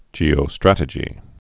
(jēō-strătə-jē)